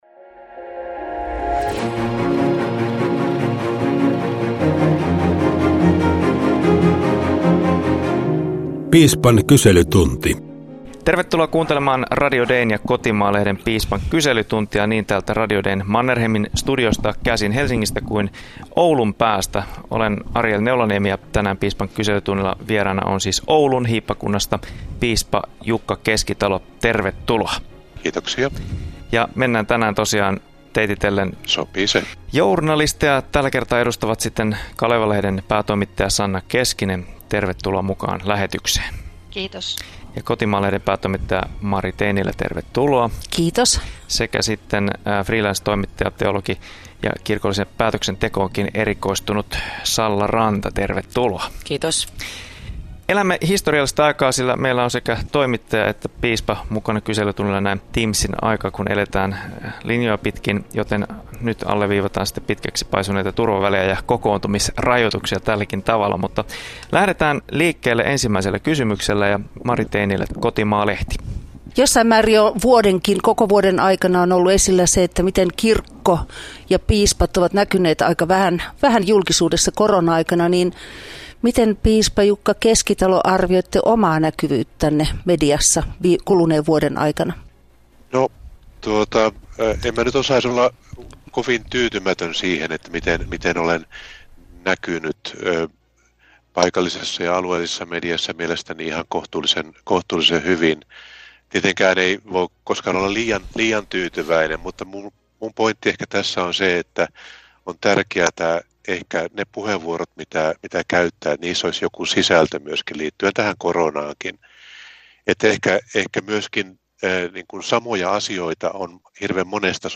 Radio Dein ja Kotimaa-lehden Piispan kyselytunti suorittaa journalistisen piispan tarkastuksen kerran kuukaudessa. Tällä kertaa vastausvuorossa on Oulun hiippakunnan piispa Jukka Keskitalo.